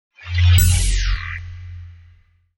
UI_SFX_Pack_61_50.wav